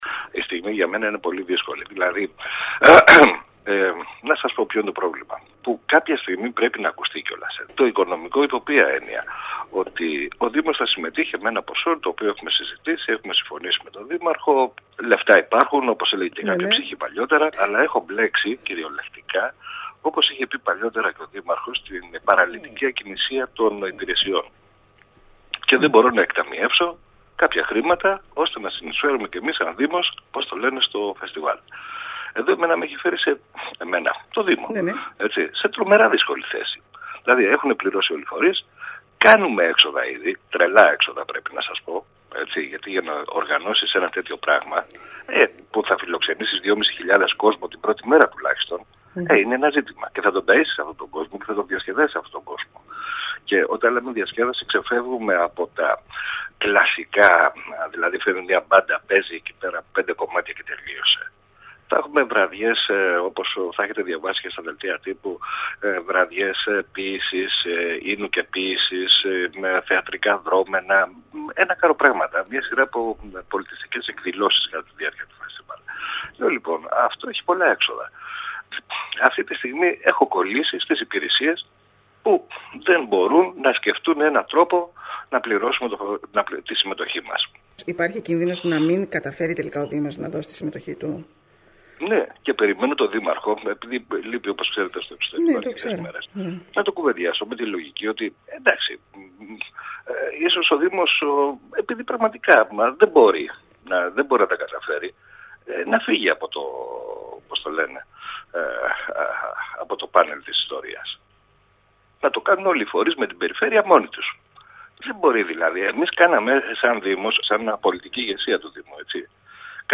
“Σημαντικά προβλήματα στη διοργάνωση και πραγματοποίηση του 1ου γαστρονομικού φεστιβάλ προκαλεί η “παραλυτική ακινησία” των υπηρεσιών του δήμου”, όπως χαρακτηριστικά ανέφερε μιλώντας στην ΕΡΤ Κέρκυρας ο αντιδήμαρχος τουρισμού Βασίλης Καββαδίας.